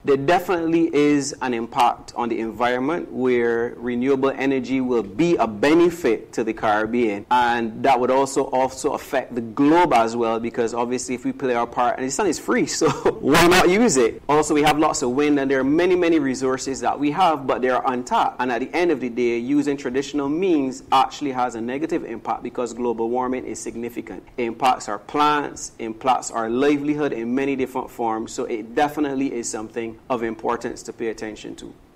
CARICOM Energy Month – A Panel Discussion